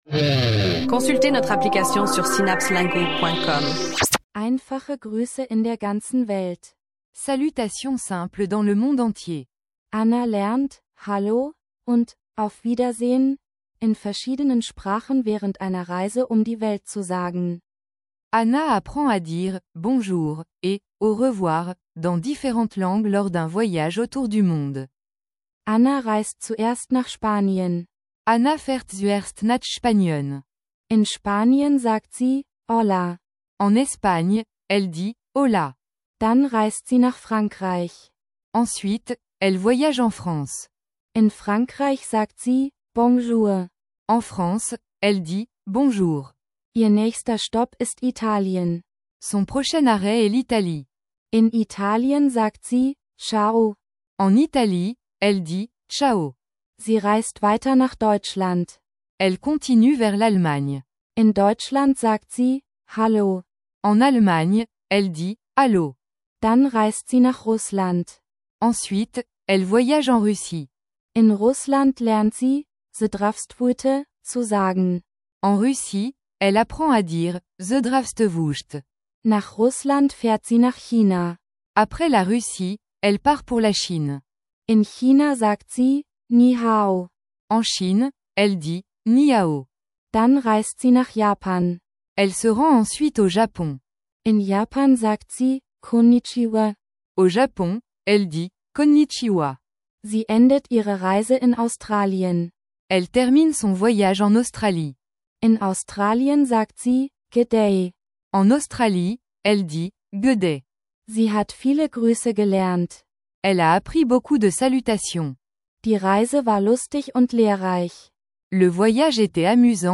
Plongez dans un monde passionnant d’apprentissage des langues avec des textes d’apprentissage divertissants et des chansons entraînantes dans différentes combinaisons de langues.
Écoutez, apprenez et profitez de mélodies accrocheuses qui vous aideront à maîtriser la langue de manière ludique.